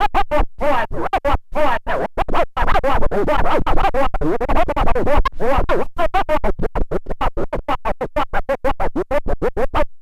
Track 14 - Turntable Scratches 01.wav